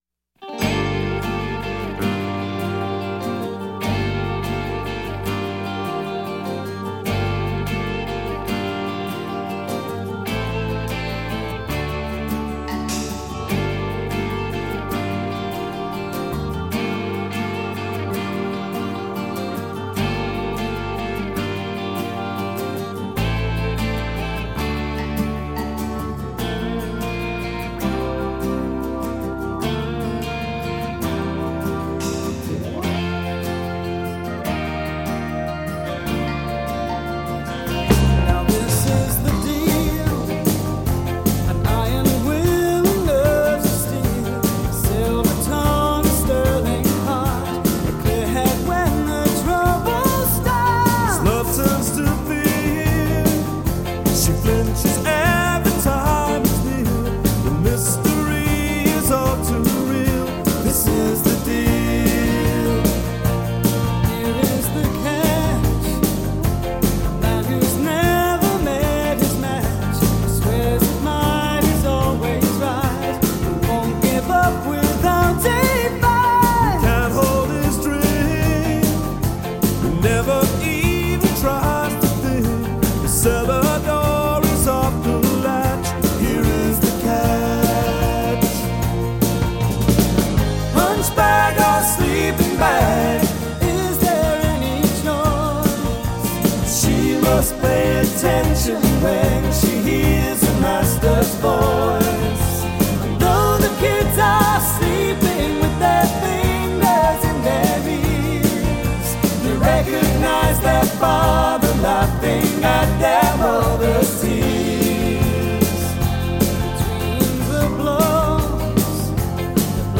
British folk rock